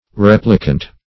Replicant \Rep"li*cant\ (r?p"l?-kant)